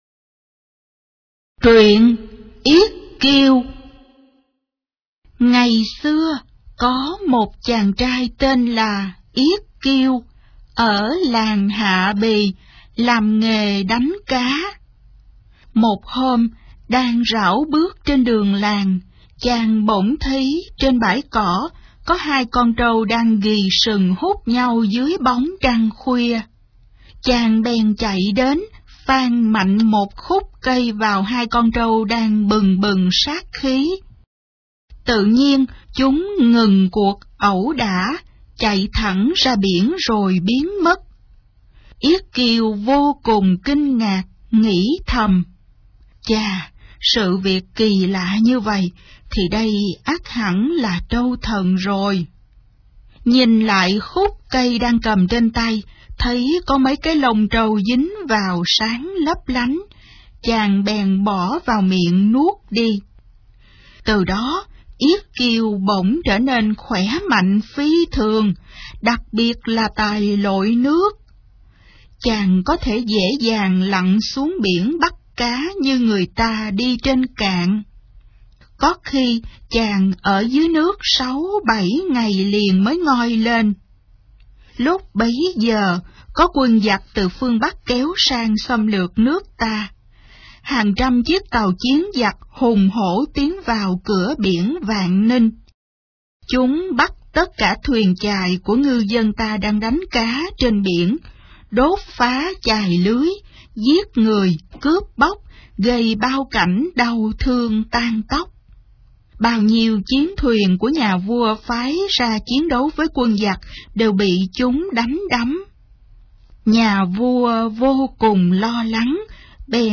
Sách nói | Truyện cổ tích Việt Nam. 08